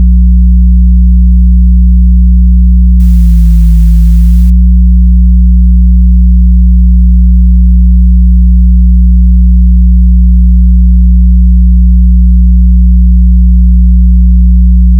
Ця зубчаста лінія — Тріск Баркгаузена — це звук атомів заліза, що борються за вирівнювання з магнітним полем.
Судова соніфікація Баркгаузена
Прислухайтеся до тріску.